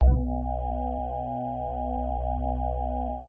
cube_rotate_1.ogg